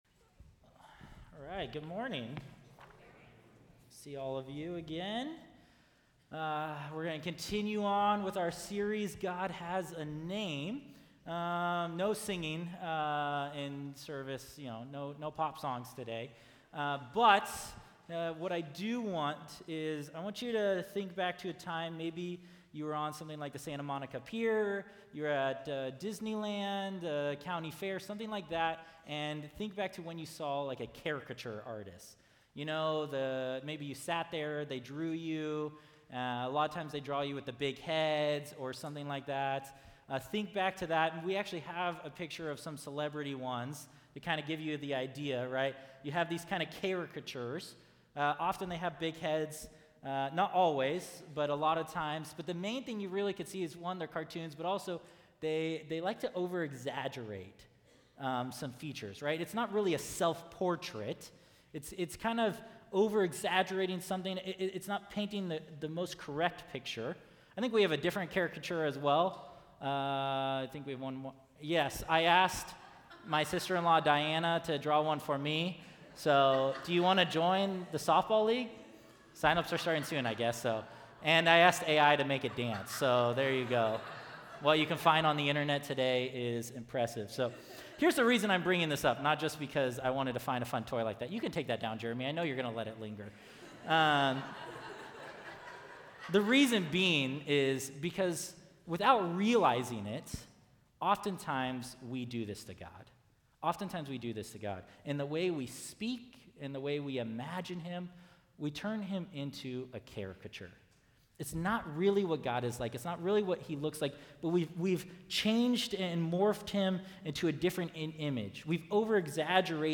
PCC Sermons